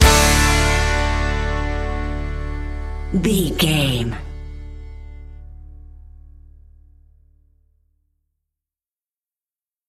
Ionian/Major
D
drums
electric piano
electric guitar
bass guitar
banjo
Pop Country
country rock
bluegrass
happy
uplifting
driving
high energy